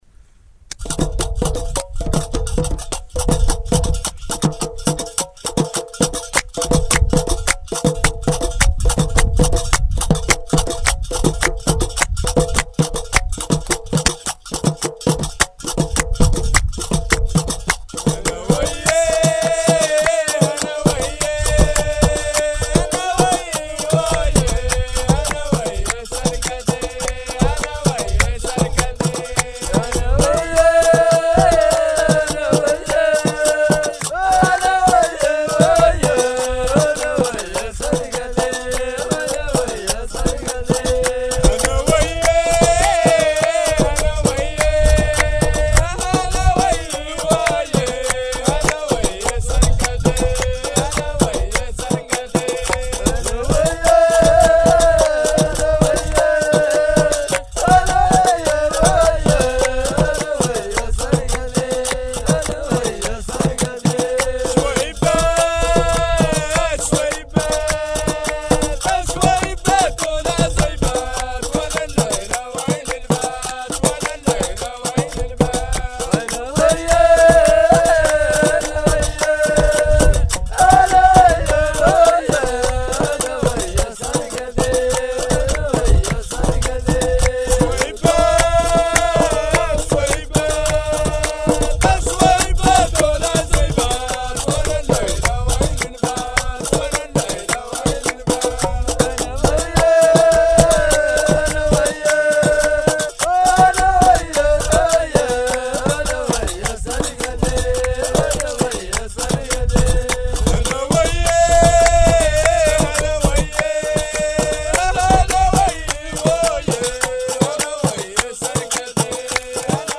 Chants traditionnels sahraouis
4 - Rythme " TBAL " ou GUITARE